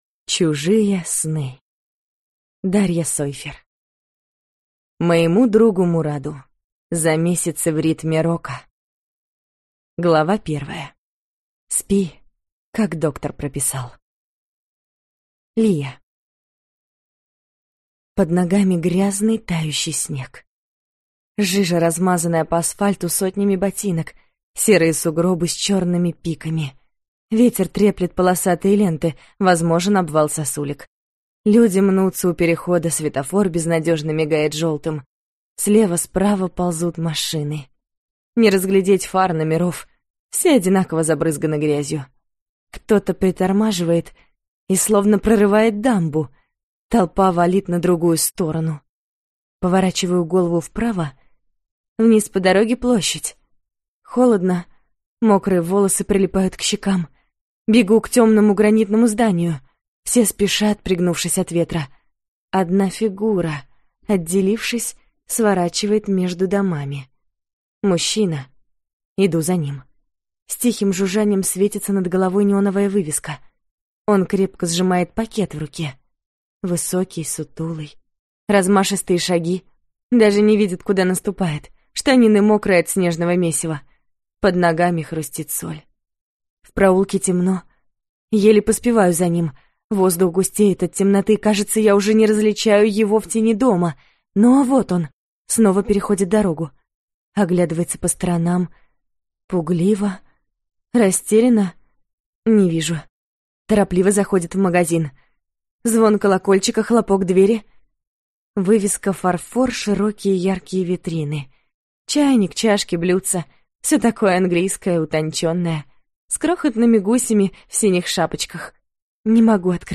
Аудиокнига Чужие сны | Библиотека аудиокниг